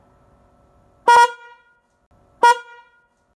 ホーン
デミオ以来の渦巻き型でシングルホーンになりました。
LoかHiかちょっと迷いましたが、Hi（510Hz）にしました。
懐かしい「プー」音。この間の抜けた音も車のキャラクター的には合っていると思うのでしばらくはこのままでいきます。